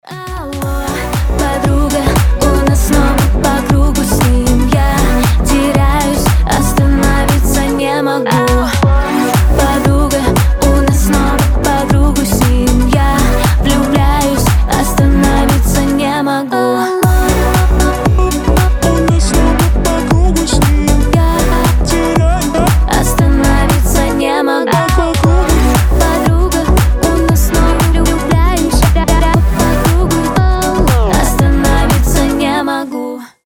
женские